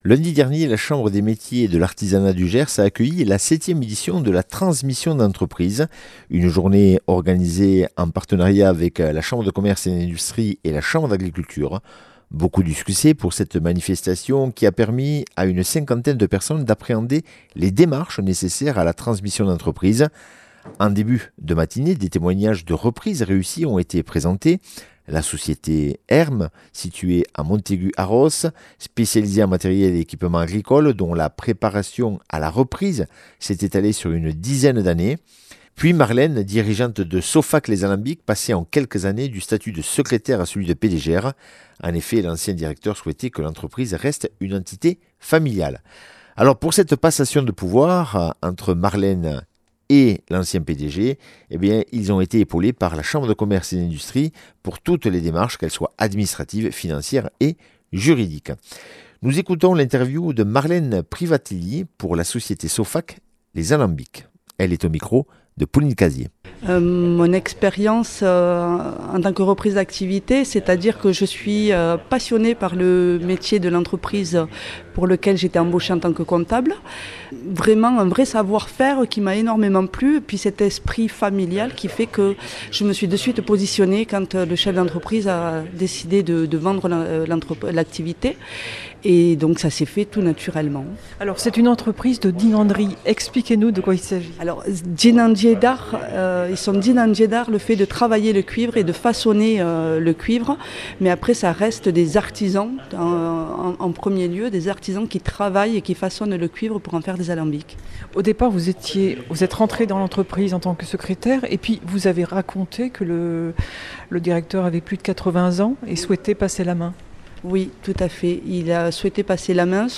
Interview et reportage du 22 nov.
Une émission présentée par
Journalistes